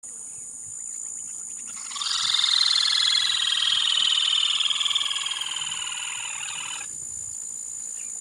Rufous-sided Crake (Laterallus melanophaius)
Life Stage: Adult
Location or protected area: Colonia Carlos Pellegrini
Condition: Wild
Certainty: Recorded vocal
burrito-comun-IBERA-D2-161.mp3